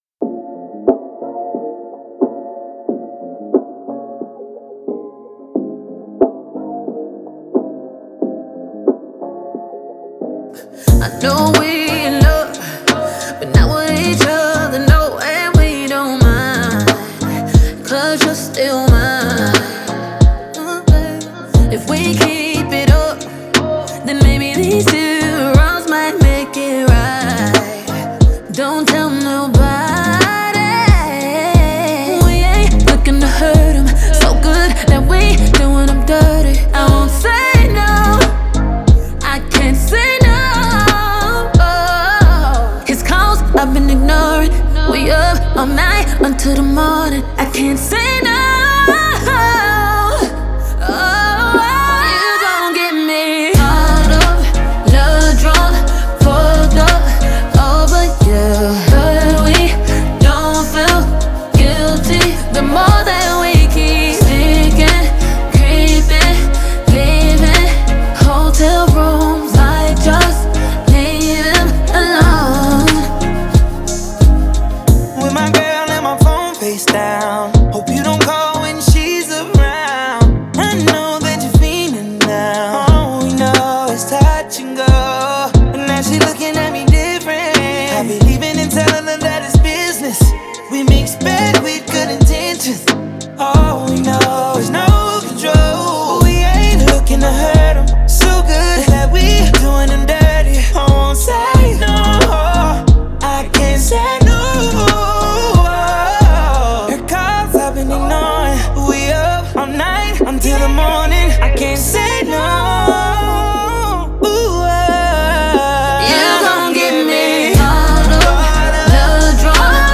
это яркая R&B композиция